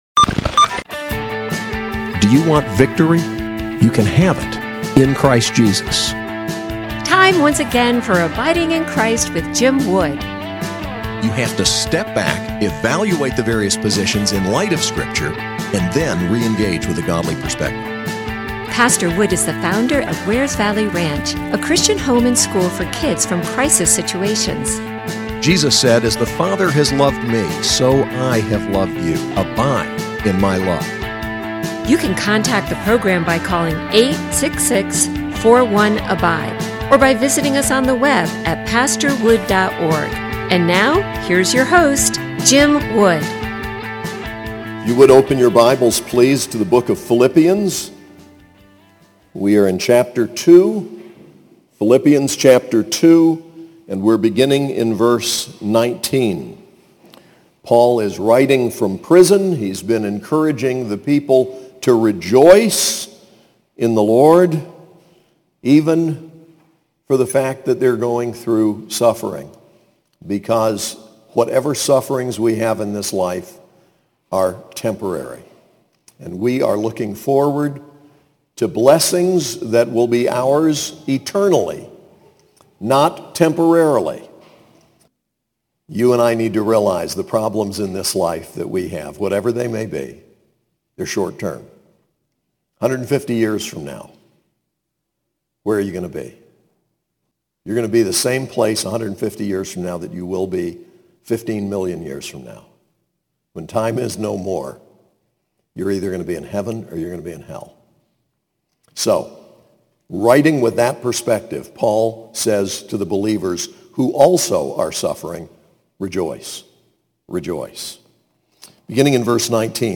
SAS Chapel: Philippians 2:19-30